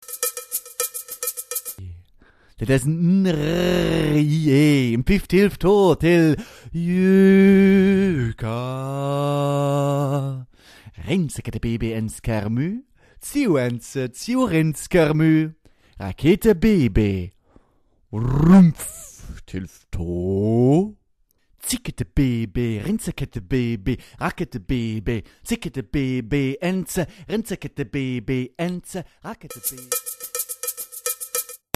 Sprecherdemos